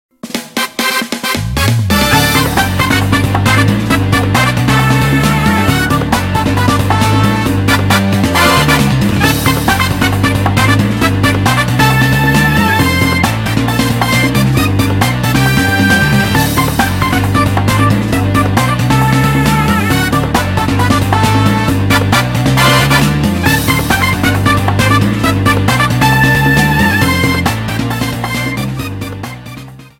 Fair use music sample